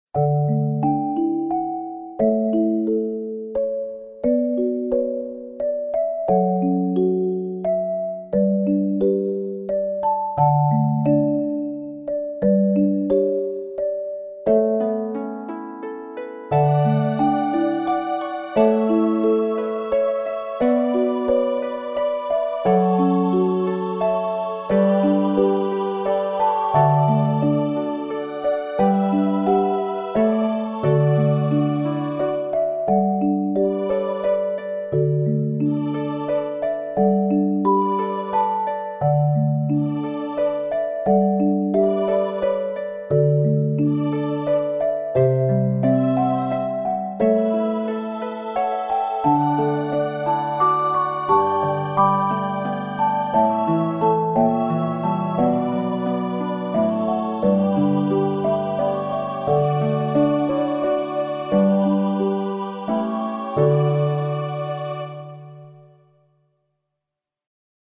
クリスマスの夜に静かに降る雪をイメージした曲。